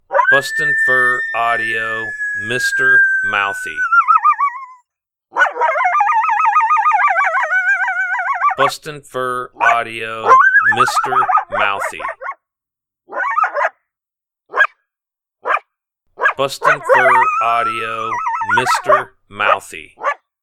Mr Mouthy is our popular Coyote MotoMoto belting out some high pitched choppy howls that will work great for instigating a territorial response.
• Product Code: howls